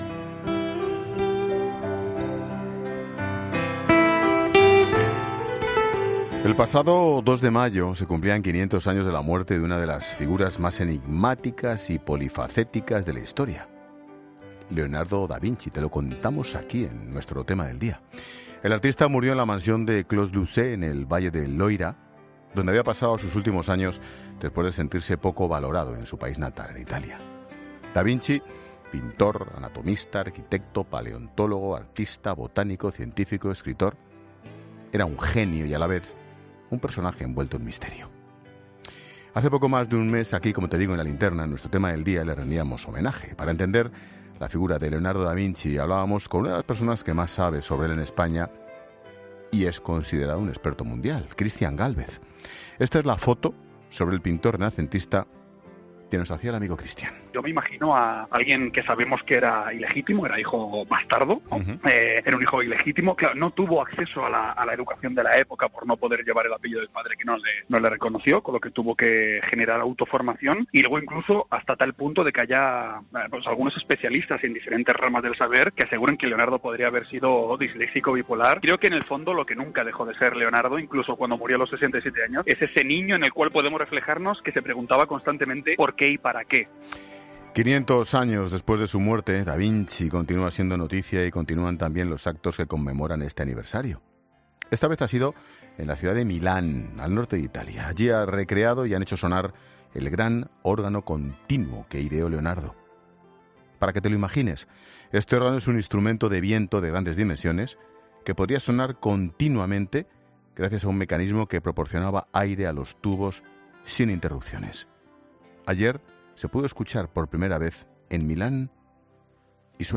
Recrean y hacen sonar el “gran órgano continuo” de Da Vinci
AUDIO: Ayer se pudo escuchar por primera vez en Milán